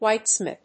アクセント・音節whíte・smìth